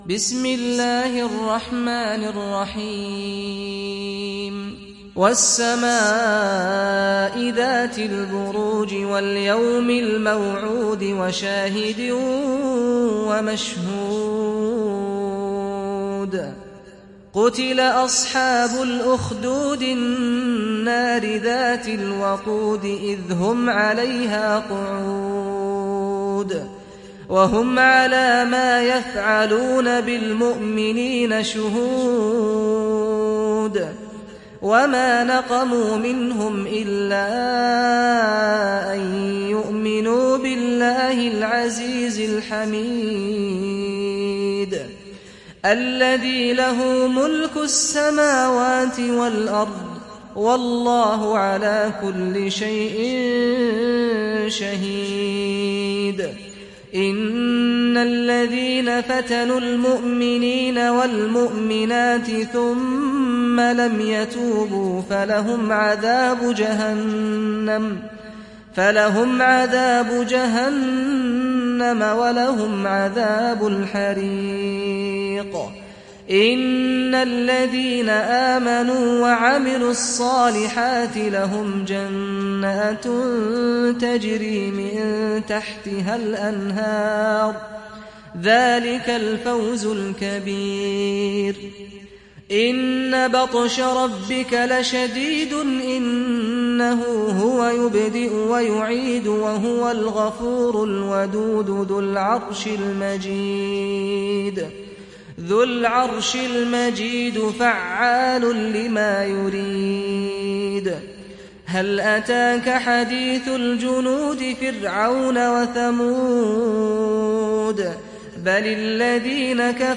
تحميل سورة البروج mp3 بصوت سعد الغامدي برواية حفص عن عاصم, تحميل استماع القرآن الكريم على الجوال mp3 كاملا بروابط مباشرة وسريعة